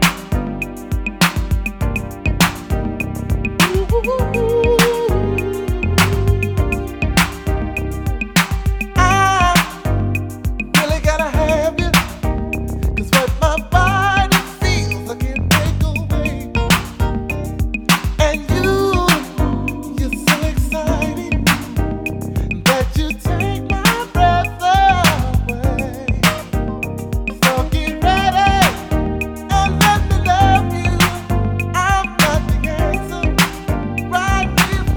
R B Soul Disco Contemporary R B Soul Quiet Storm
Жанр: R&B / Соул / Диско